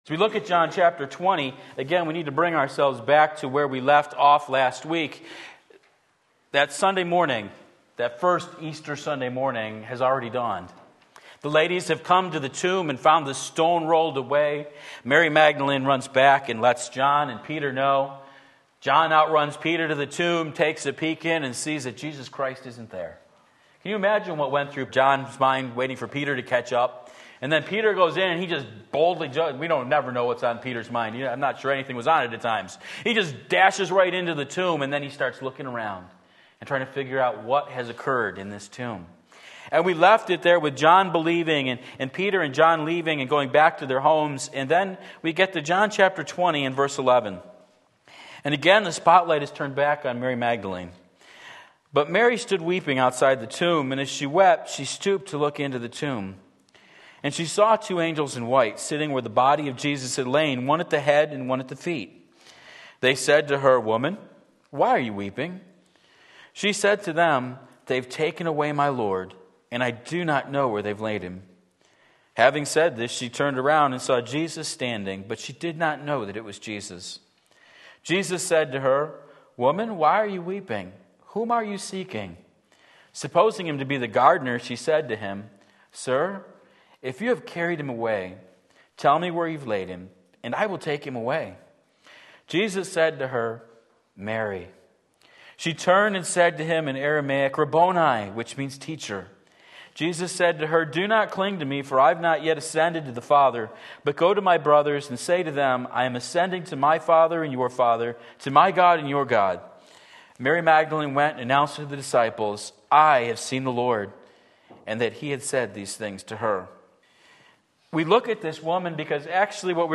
Sermon Link
Encounters with the Risen Lord John 20:11-31 Sunday Morning Service, November 12, 2017 Believe and Live!